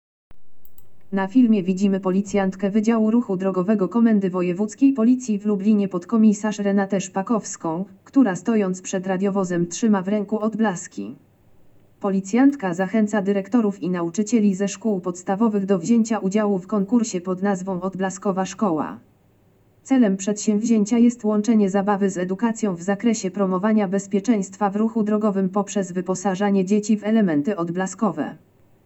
Opis nagrania: Audiodeskrypcja do filmu Rusza konkurs Odblaskowa Szkoła